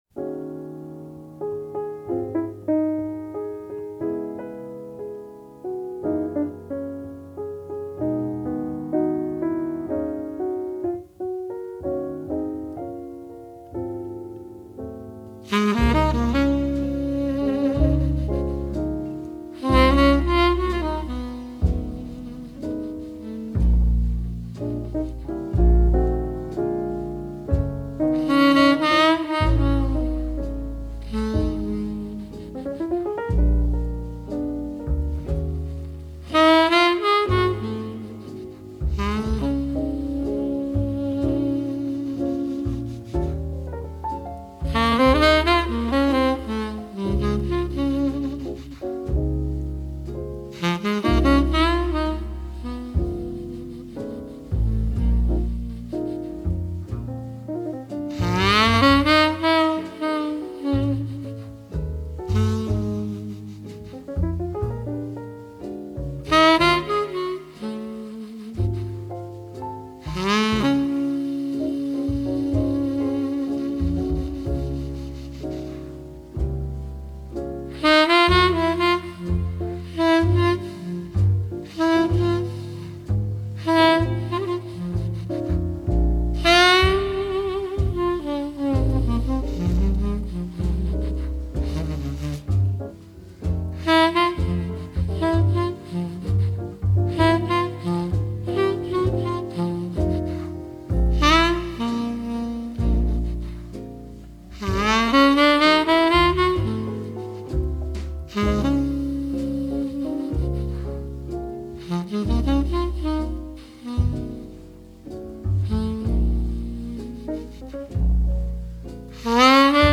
爵士及藍調 (573)
★ 如清流般柔和傾吐，撫慰人心的薩克斯風演奏！